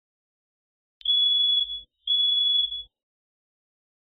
Heartbeat Fast
Heartbeat Fast is a free sfx sound effect available for download in MP3 format.
105_heartbeat_fast.mp3